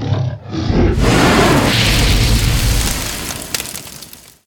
fireball.ogg